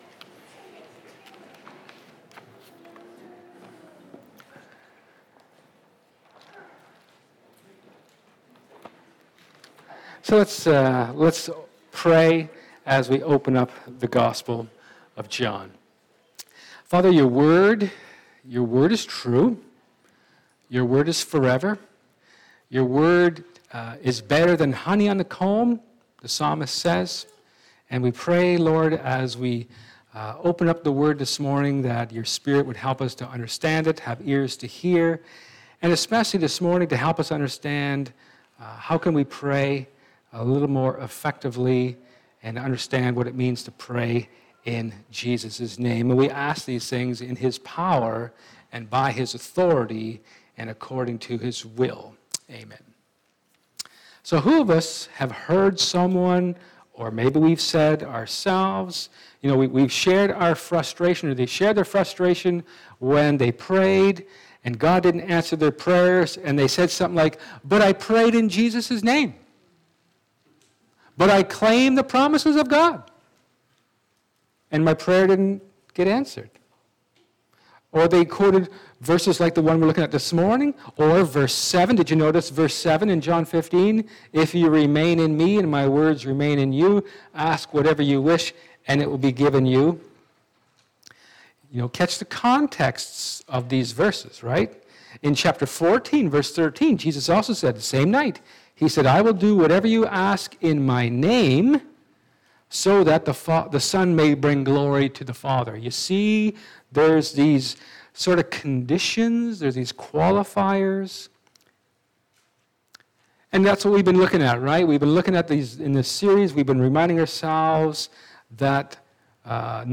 Acts 1:12-26 Service Type: Sermon